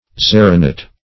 xeronate - definition of xeronate - synonyms, pronunciation, spelling from Free Dictionary Search Result for " xeronate" : The Collaborative International Dictionary of English v.0.48: Xeronate \Xe"ro*nate\, n. (Chem.)